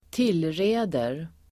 Uttal: [²t'il:re:der]